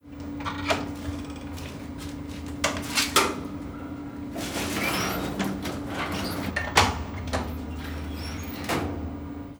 Caja registradora de un bar
caja registradora